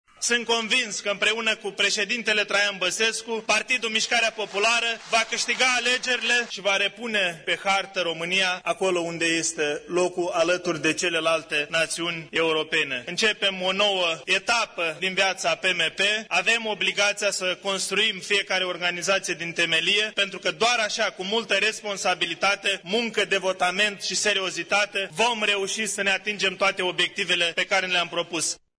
El a fost ales de cei aproximativ 700 de delegaţi prezenţi astăzi la Congresul formaţiunii.